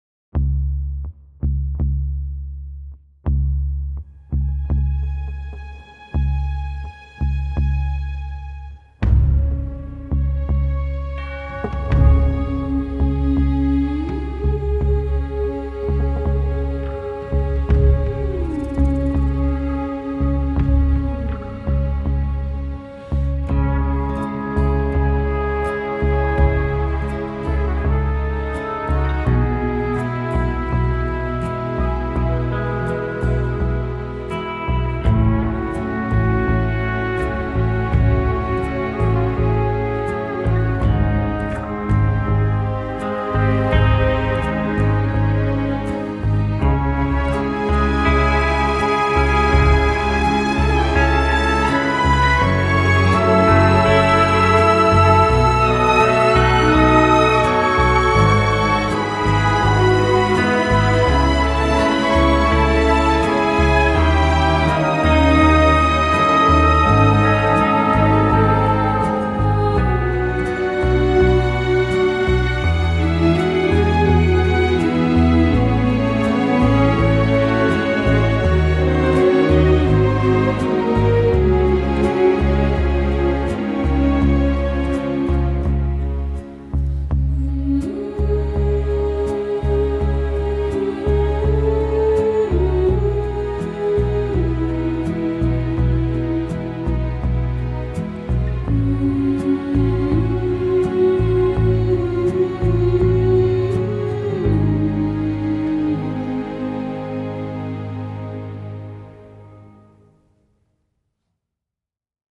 Que nenni, c’est toujours entraînant et dosé avec goût.
Quelques chœurs féminins, nonnes obligent.